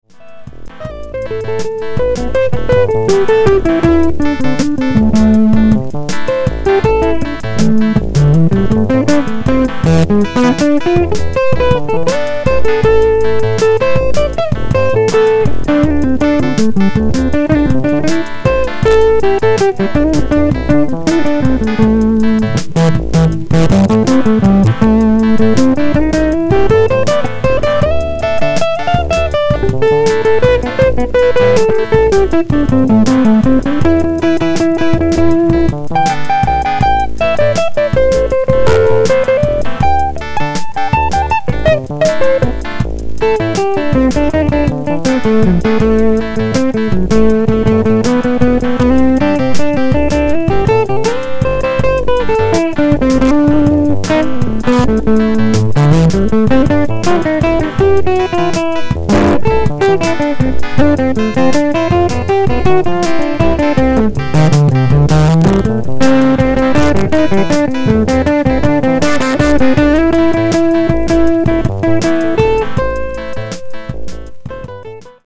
Ich habe schon reichlich Demo-Aufnahmen in der Wohnung mit meinem Übungs-Amp gemacht, den ich mit Mikro abgenommen habe.
Klangbeispiel mit der Telecaster. Die Verzerrungen in dieser Aufnahme resultieren aus einer gewissen Überforderung des empfindlichen Aufnahme-Mikros, welches zu nah am Lautsprecher angeordnet war. Die EL 95 bringt etwa 3 W.
dontplay_solo_demo_telecaster.mp3